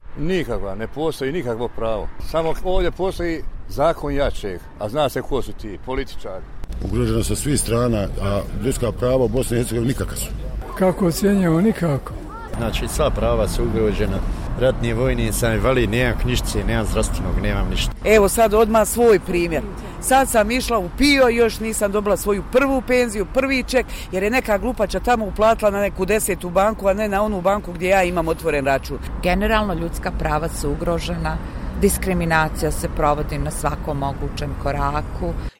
I ostala osnovna ljudska prava su ugrožena, navode građani: